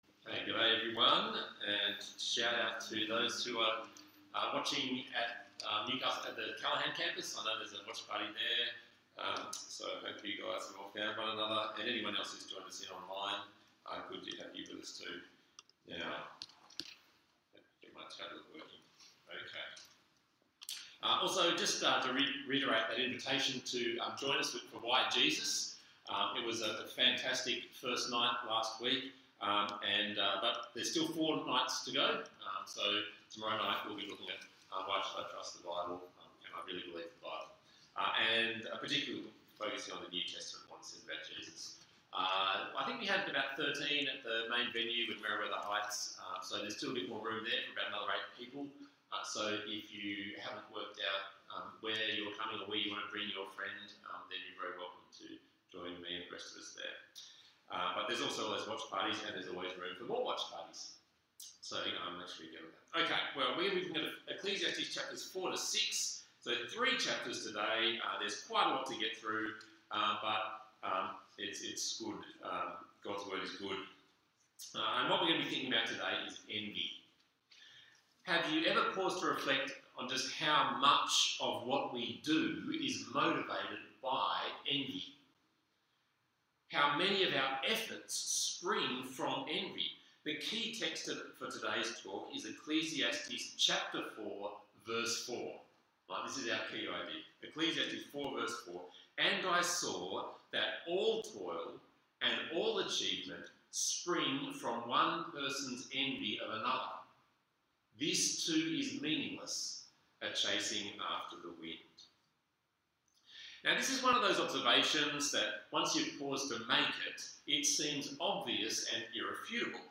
Due to technical difficulties, a portion of this week’s Bible talk was not recorded so there is a jump around 33:35.
Talk Type: Bible Talk